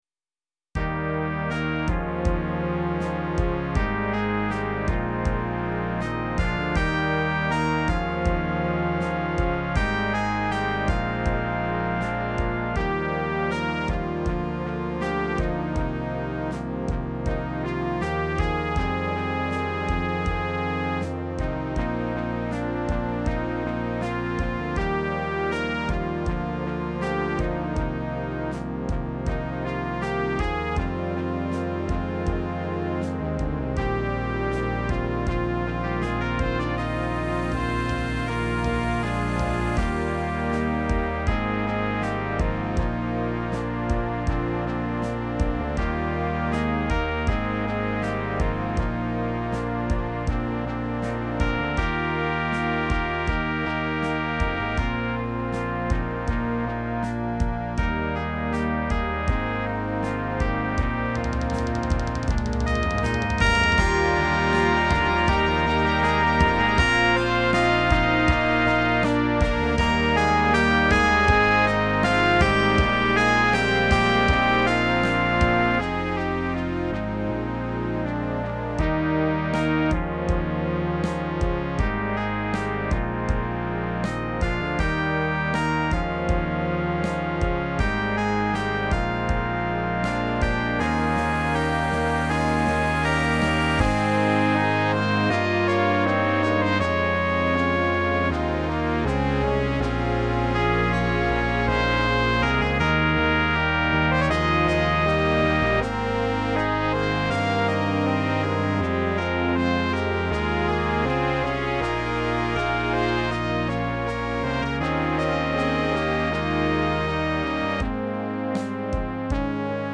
(Song Arrangement)